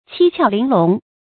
七竅玲瓏 注音： ㄑㄧ ㄑㄧㄠˋ ㄌㄧㄥˊ ㄌㄨㄙˊ 讀音讀法： 意思解釋： 形容聰明靈巧。